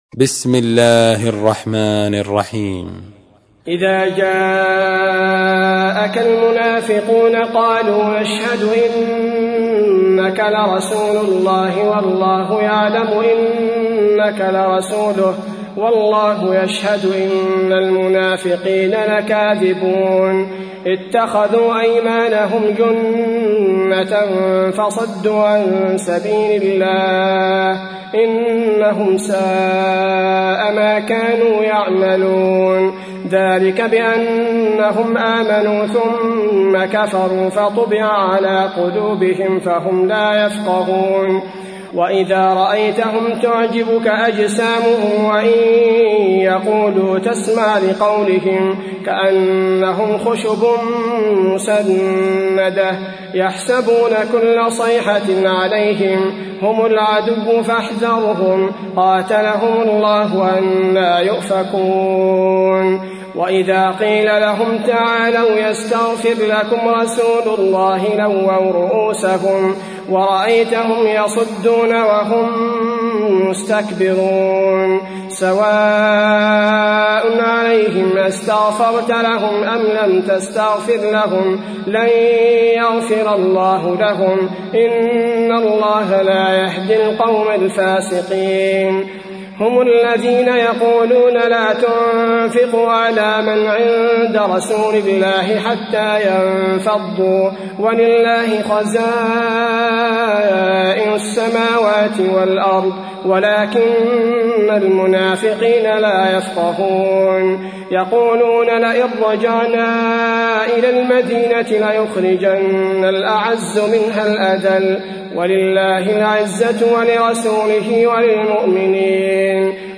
تحميل : 63. سورة المنافقون / القارئ عبد البارئ الثبيتي / القرآن الكريم / موقع يا حسين